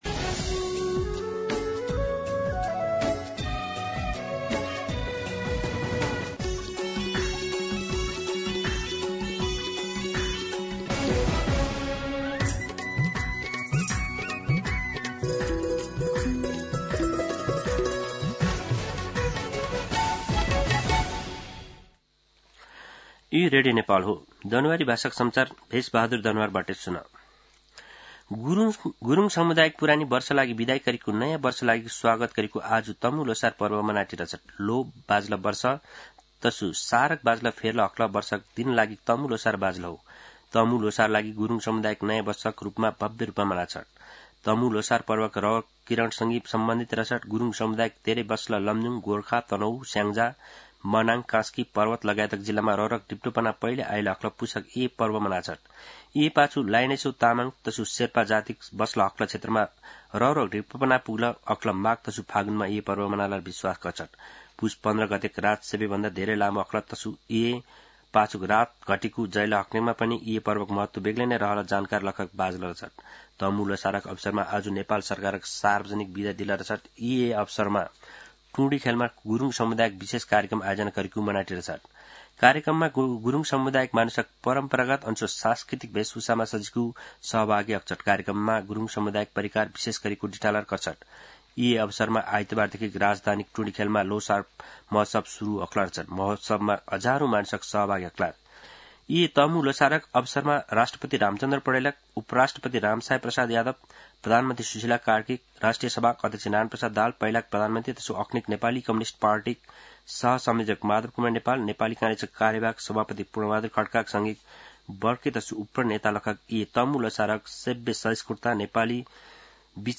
दनुवार भाषामा समाचार : १५ पुष , २०८२
Danuwar-News-15.mp3